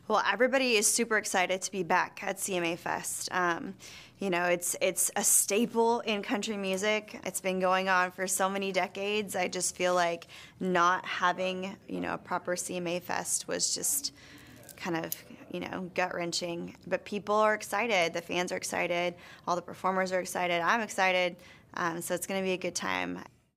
Audio / Carrie Underwood talks about CMA Fest 2022.